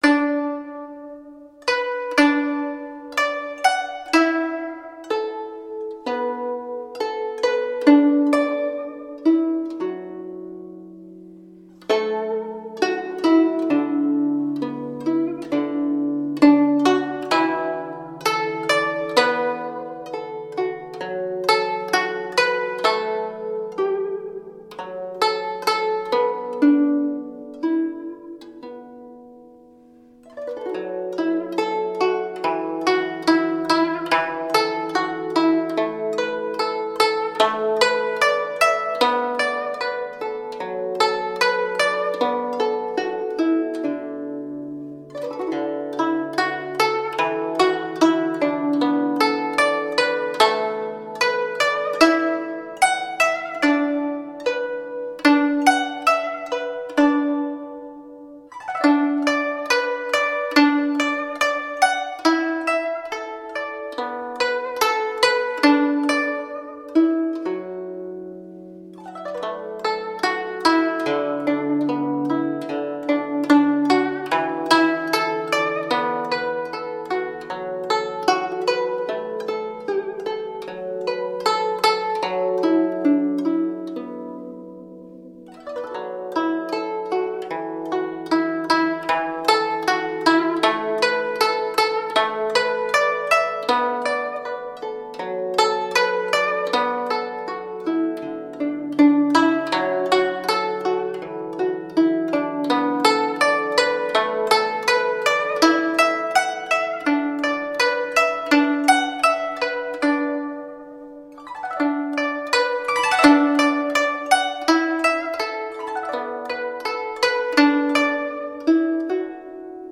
佛音 冥想 佛教音乐